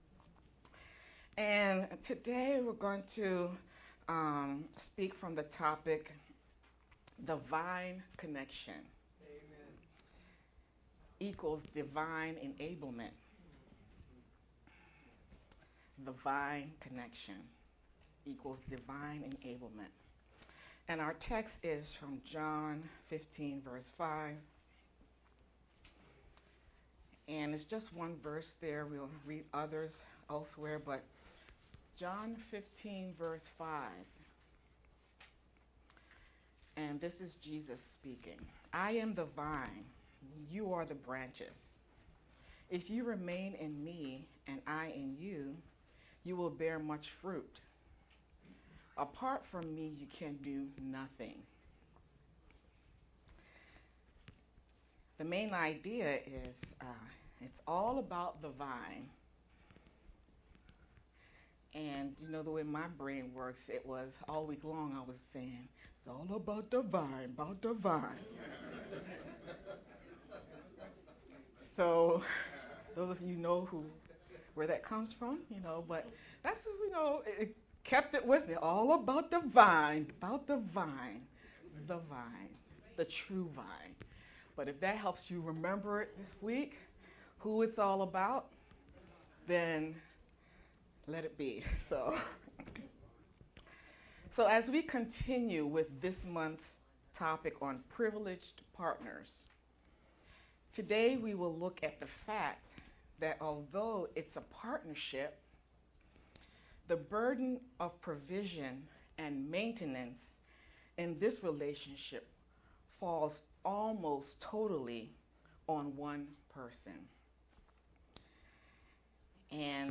March-15th-VBCC-Sermon-only-CD.mp3